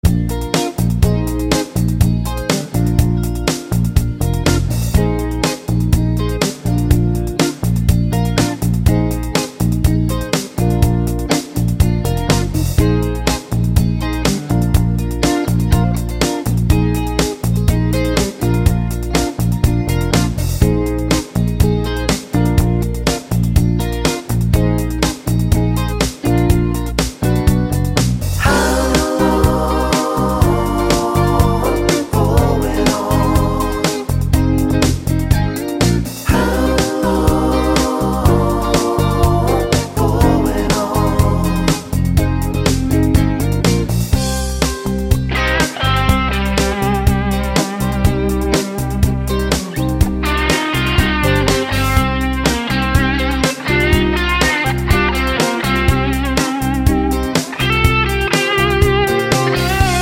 Minus Electric Piano Pop (1970s) 3:30 Buy £1.50